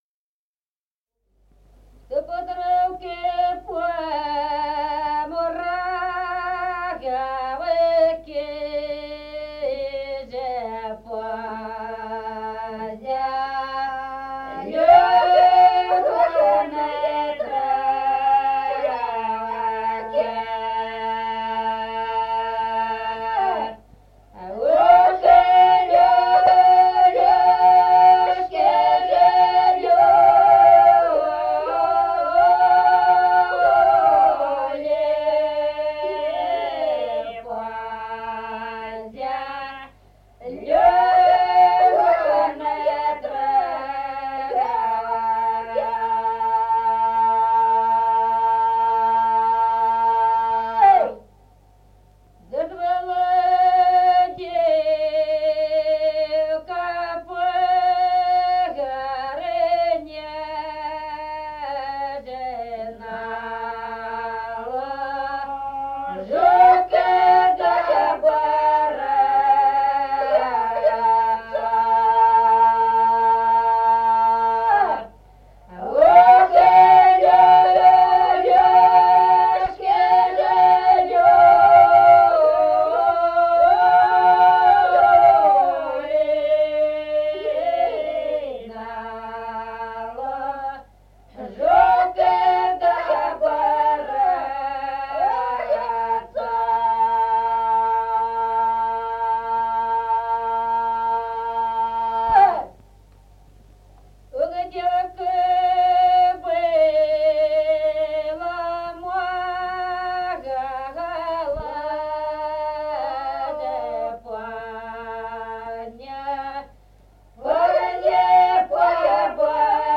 1953 г., с. Остроглядово.